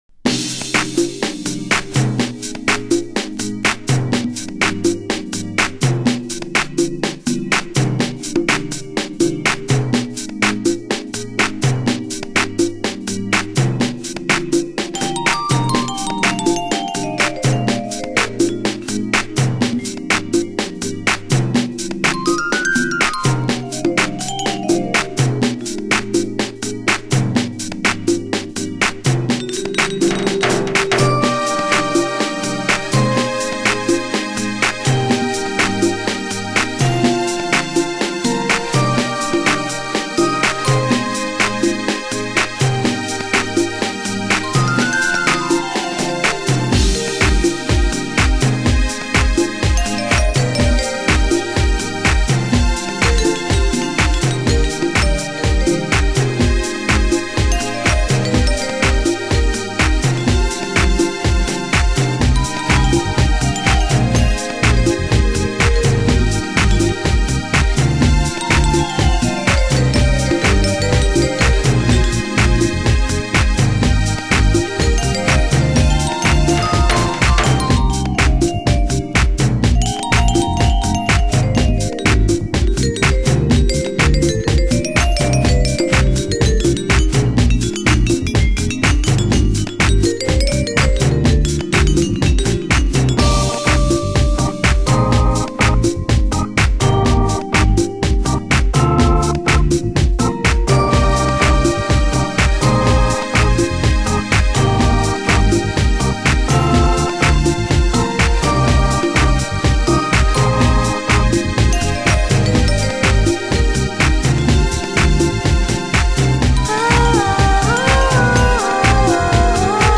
HOUSE MUSIC